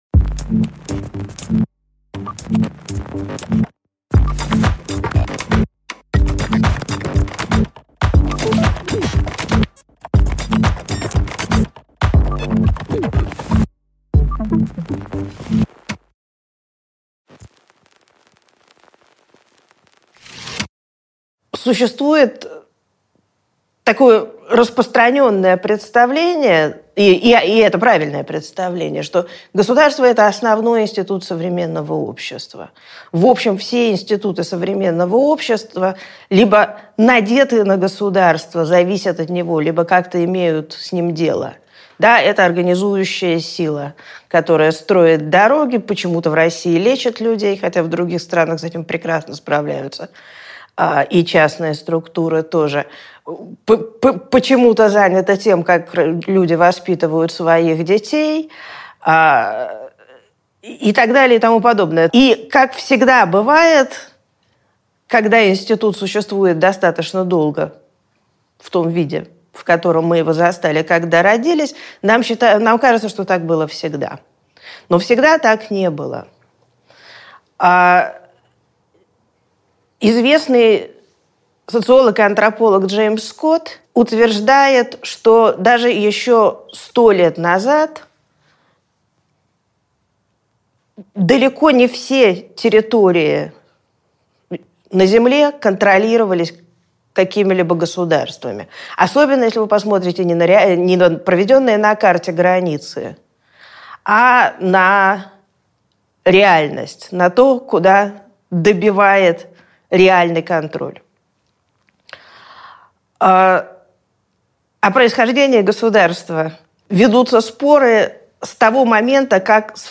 Аудиокнига Пределы контроля | Библиотека аудиокниг
Прослушать и бесплатно скачать фрагмент аудиокниги